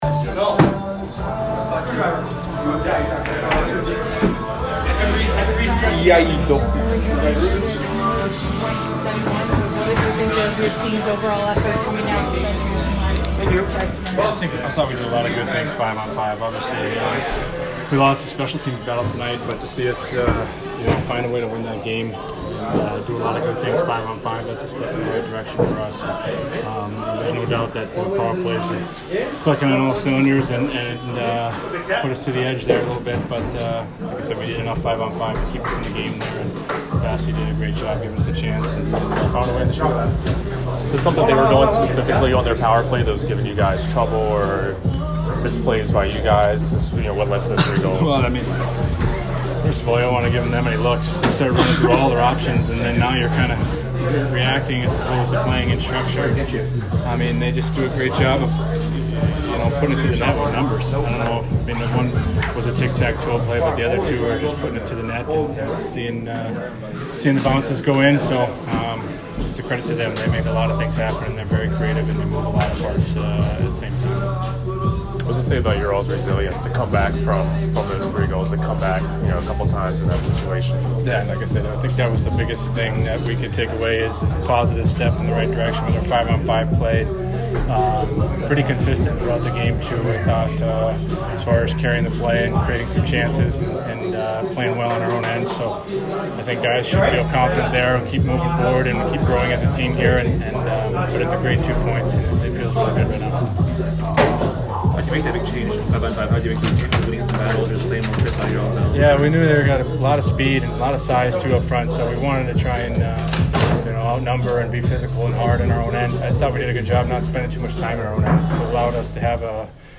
Ryan McDonagh Post - Game At Boston Oct. 17